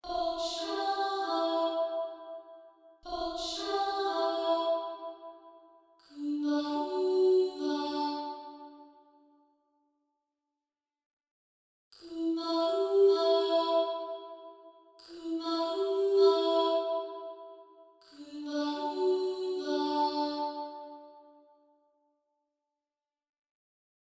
Nun hätte ich gerne noch eine Frauenstimme, die die Verse mit singt.
… bringen aber nur das hier zustande: